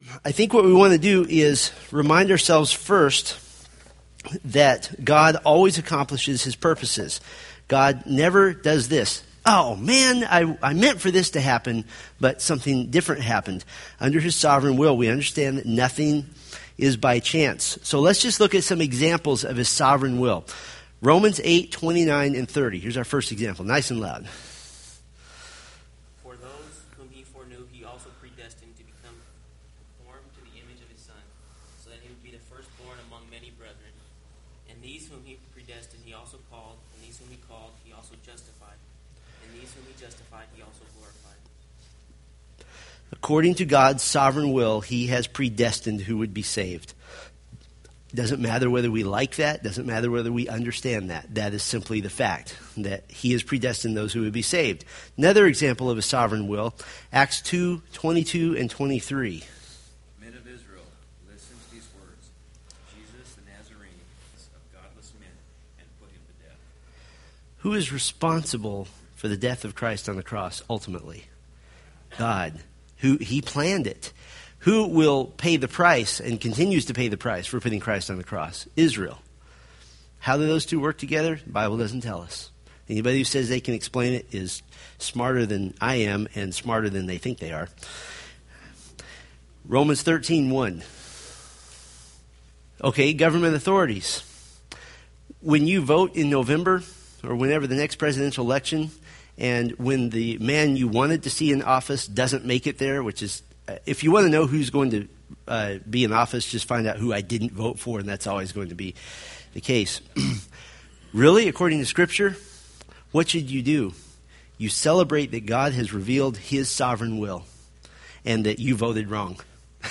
Date: Oct 6, 2013 Series: Fundamentals of the Faith Grouping: Sunday School (Adult) More: Download MP3